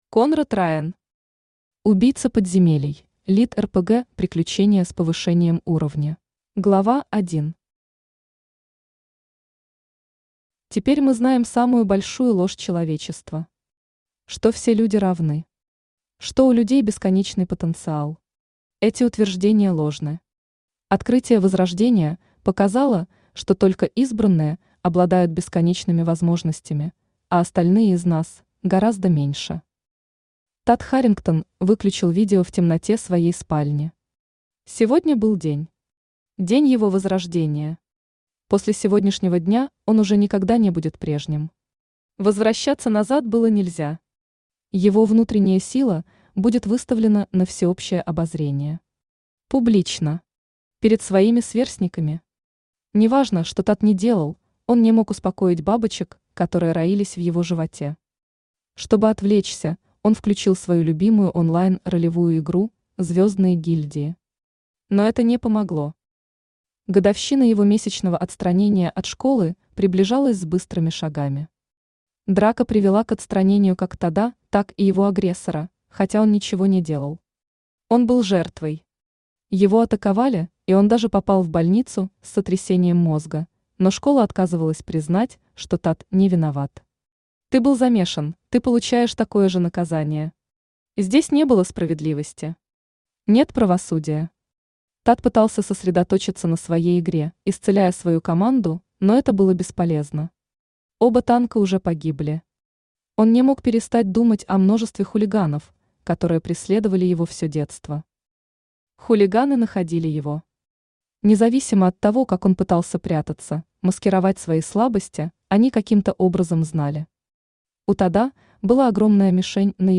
Аудиокнига Убийца Подземелий: ЛитРПГ Приключение с Повышением Уровня | Библиотека аудиокниг
Aудиокнига Убийца Подземелий: ЛитРПГ Приключение с Повышением Уровня Автор Конрад Райан Читает аудиокнигу Авточтец ЛитРес.